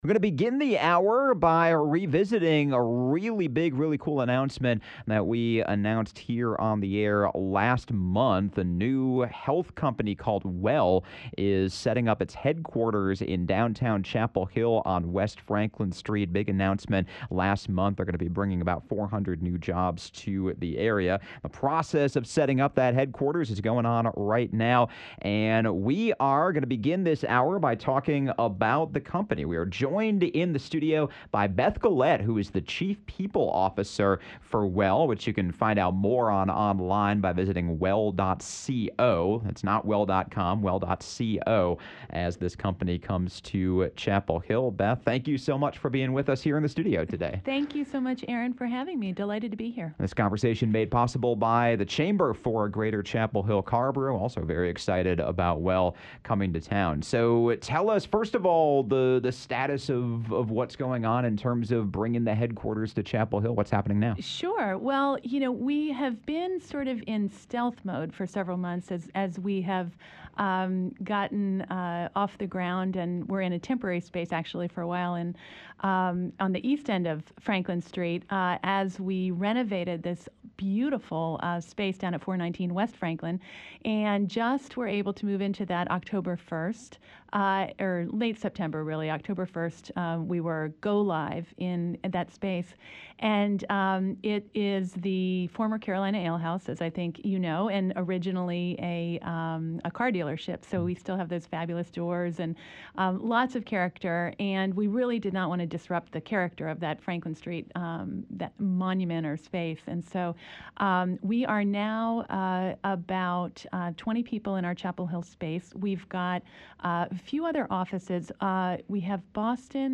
Conversation sponsored by the Chamber for a Greater Chapel Hill-Carrboro.